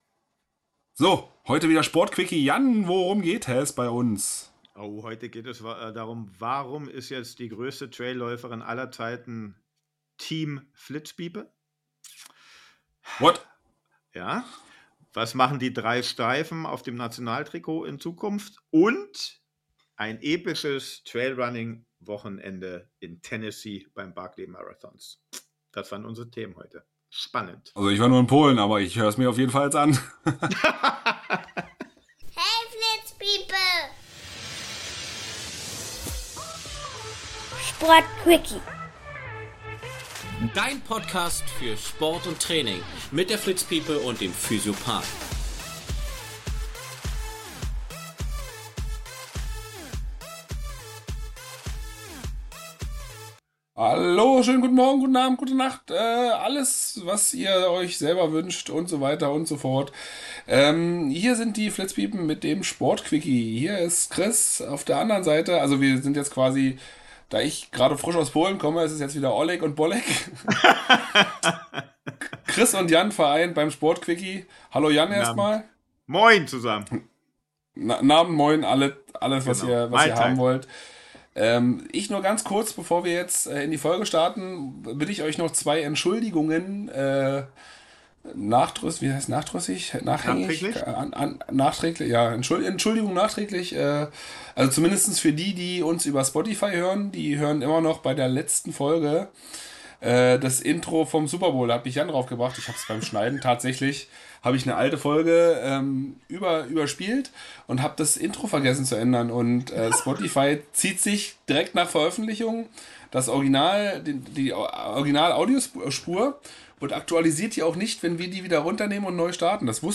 Salomon Profis im Interview, deutsches Trikot und alles zum Barkley Marathon